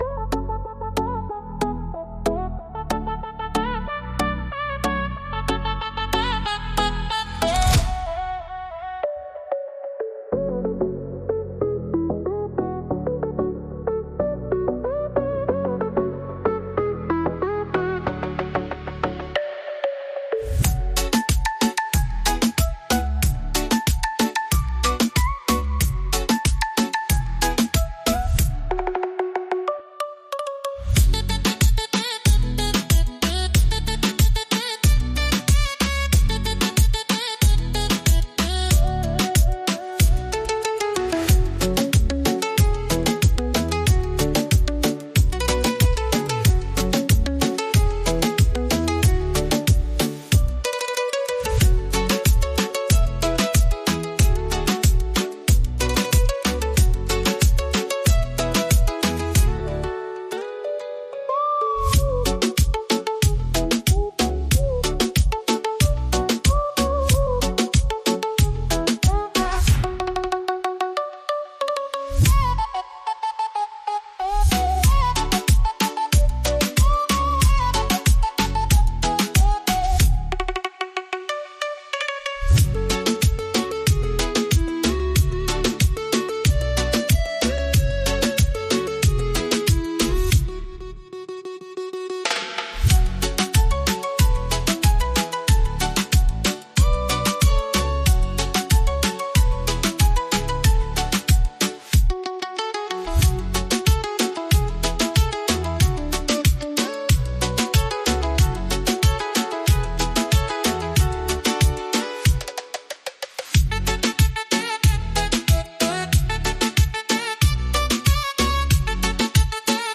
Beat Reggaeton Instrumental
F#m